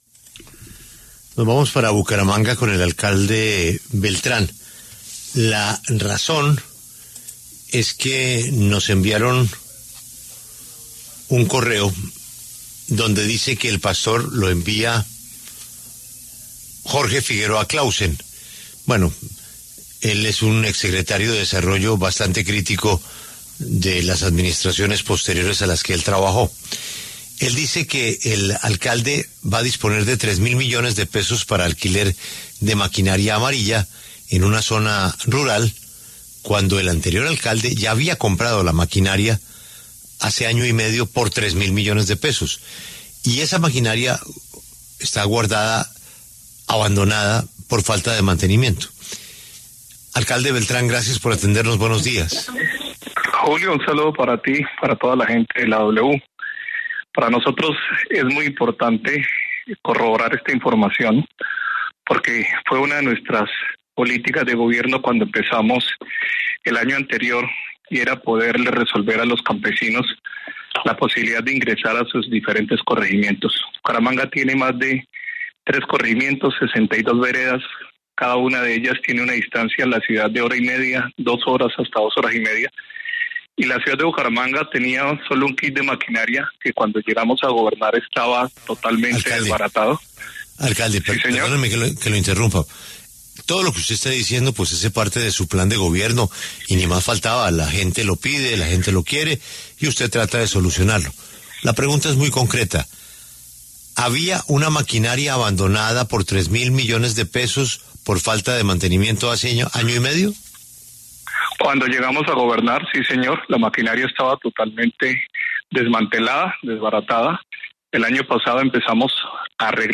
El exsecretario de desarrollo social de Bucaramanga, Jorge Figueroa Clausen, envió un correo a La W para denunciar que la actual administración habría hecho un millonario contrato para maquinaria amarilla cuando en el 2022 ya se había adquirido una y que no se estaba utilizando, con Julio Sánchez Cristo habló el alcalde Jaime Andrés Beltrán sobre esta situación.